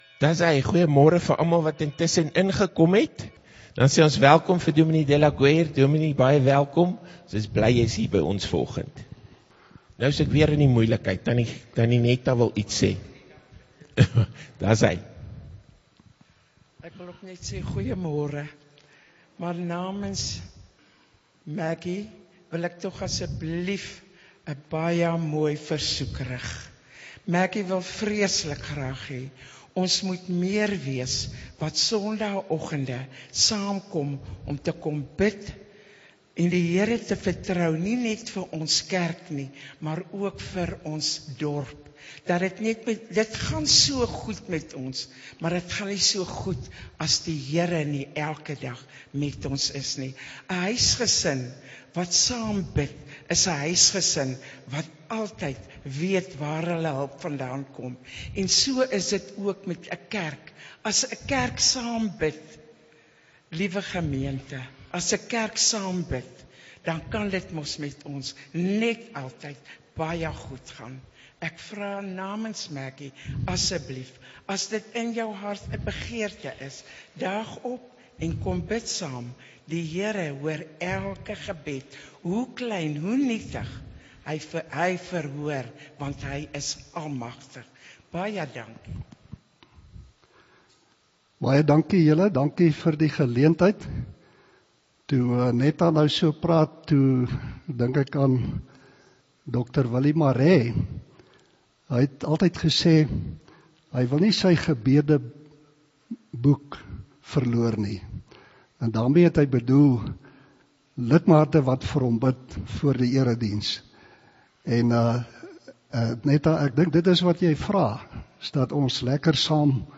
Kategorie: Oggenddiens